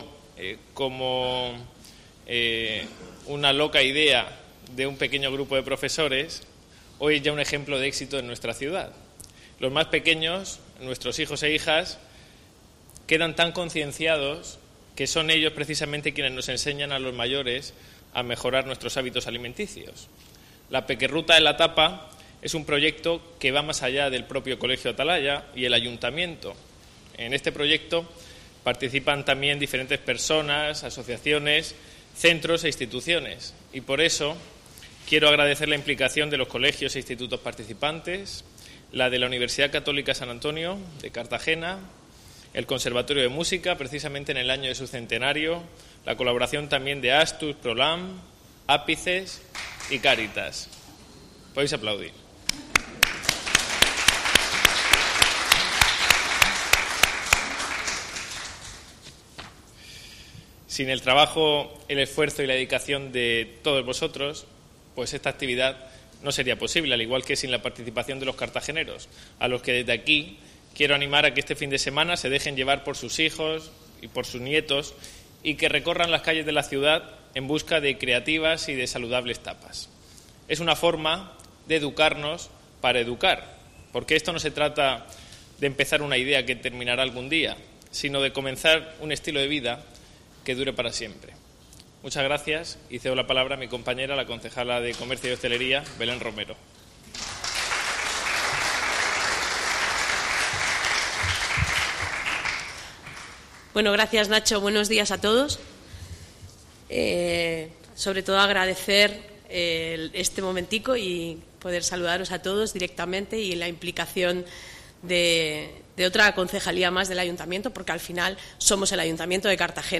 Enlace a Presentación de la Ruta de la Tapa Infantil.
Educación del Ayuntamiento de Cartagena ha presentado este 26 de mayo la cuarta edición la IV peque ruta de la tapa infantil. El concejal de Eduación, Ignacio Jáudenes, y la edil de Comercio,Belén Romero, han informado que es un proyecto que promueve la salud infantil en Cartagena organizada conjuntamente con el centro educativo Atalaya y en el que colaboran otras áreas también como la de Comercio; así como la ADLE, otras colaboraciones externas como UCAM, Conservatorio de Música de Cartagena, entidades como Astus-Prolam, Ápices; y centros educativos de Cartagena tanto colegios como institutos